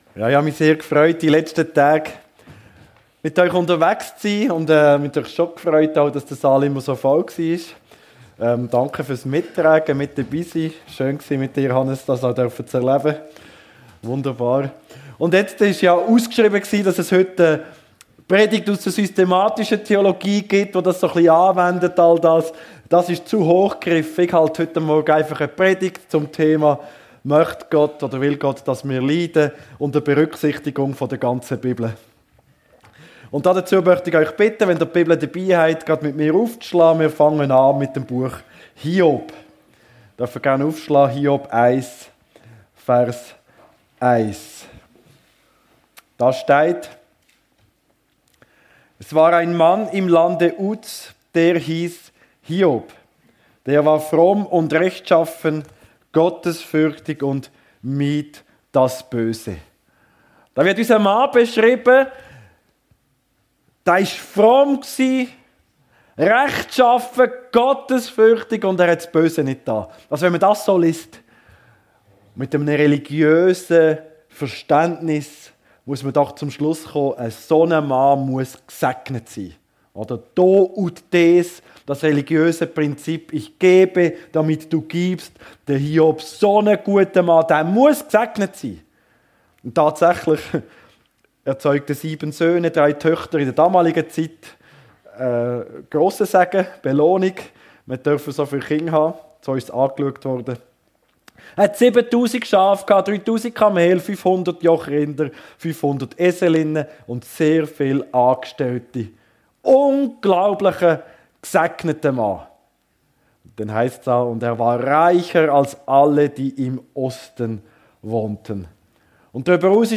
Bibetlage 2025: Will Gott, dass wir leiden? ~ FEG Sumiswald - Predigten Podcast